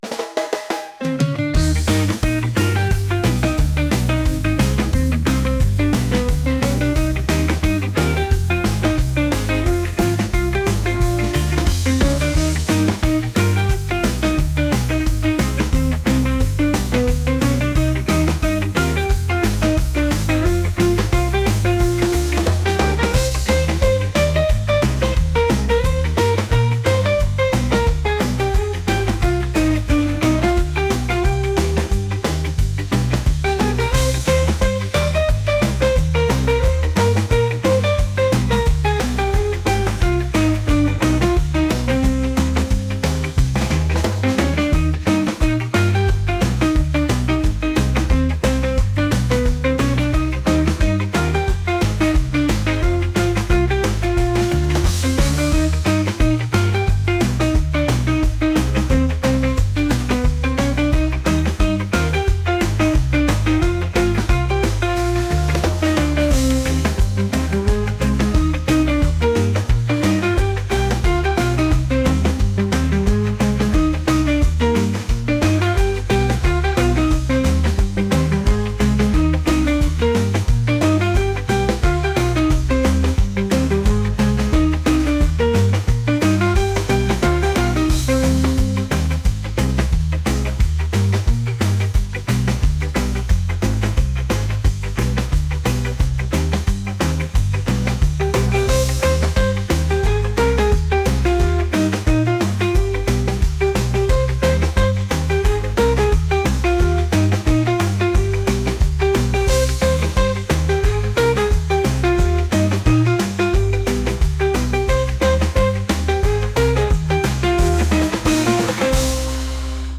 reggae | jazz